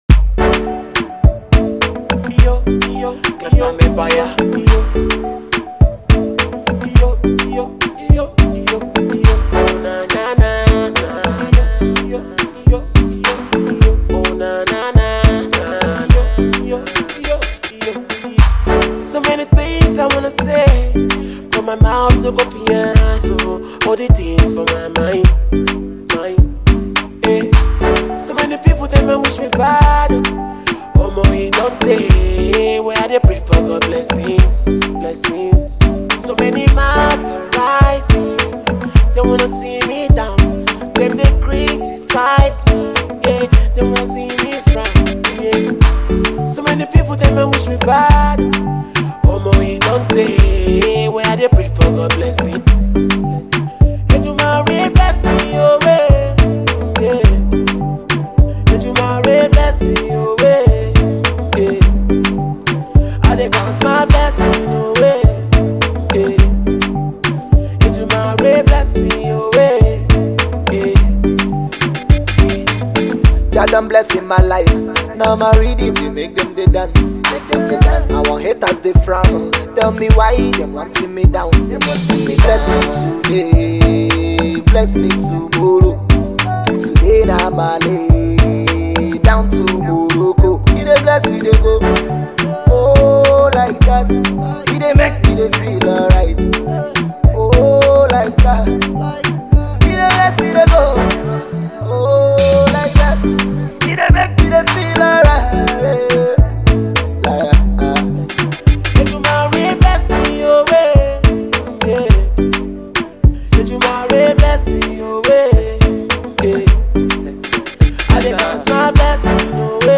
mellow tune